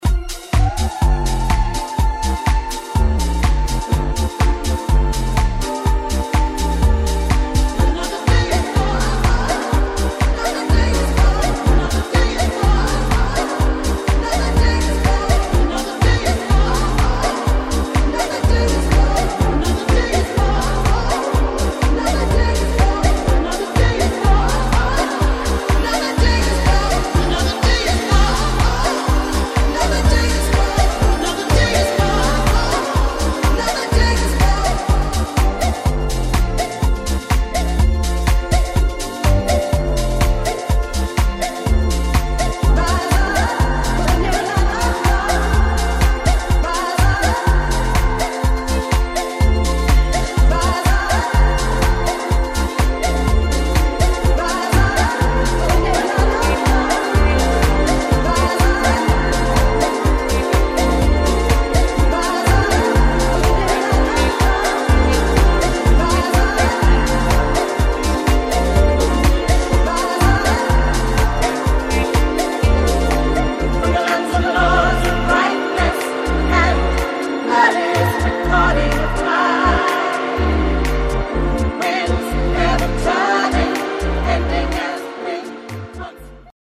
[ SOUL / FUNK / LATIN ]